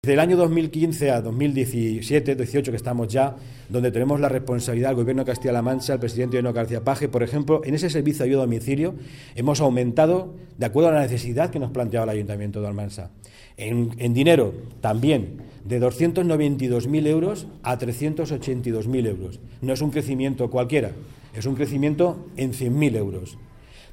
Consejería de Bienestar Social Martes, 3 Abril 2018 - 2:30pm El delegado de la Junta en Albacete, Pedro Antonio Ruiz Santos ha declarado que en la presente Legislatura, se ha incrementado la Ayuda a Domicilio en un 30 por ciento en Almansa, pasando de 292.000 euros a 382.000 euros de 2015 a 2018. ruiz_santos-_incremento_presupuesto_sad_almansa.mp3 Descargar: Descargar Provincia: Albacete